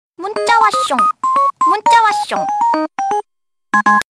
Cute korean sms tones ringtone free download
Message Tones